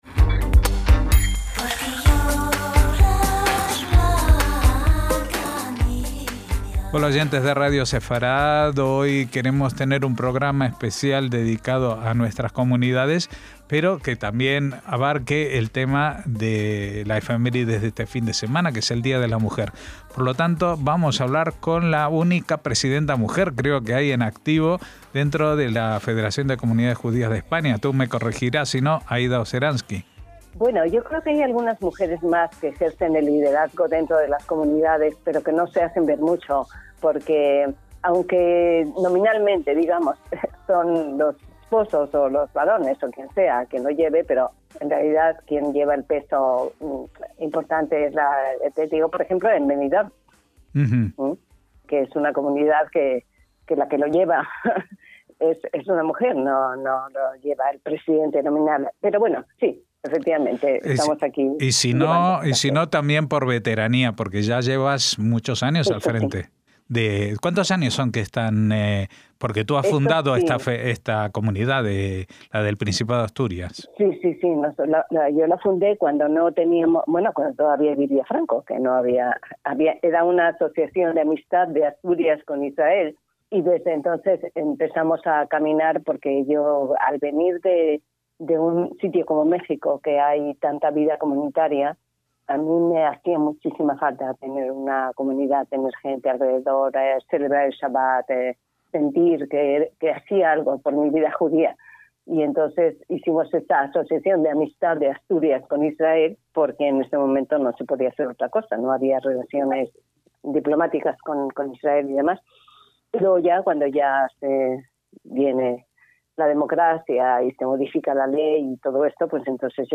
NUESTRAS COMUNIDADES - No es la primera vez que entrevistamos